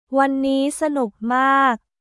ワンニー サヌック マーク